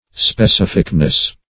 Specificness \Spe*cif"ic*ness\, n. The quality or state of being specific.
specificness.mp3